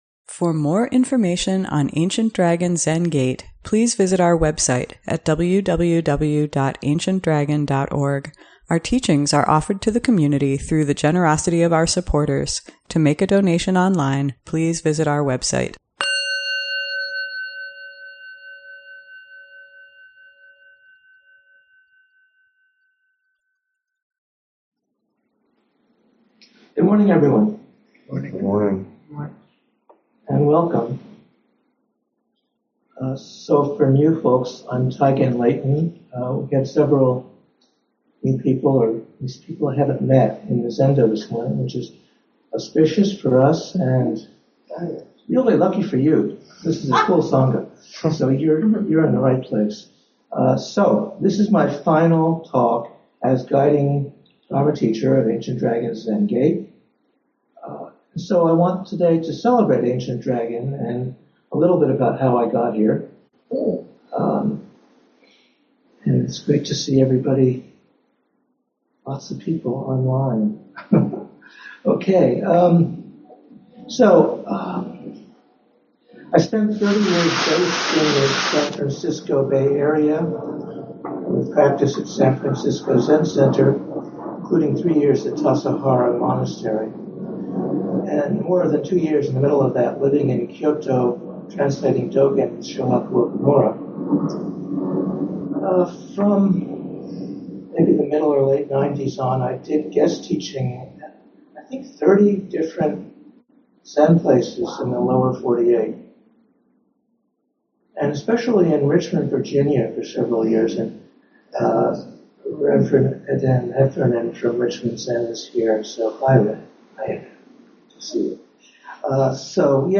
Sunday Morning Dharma Talk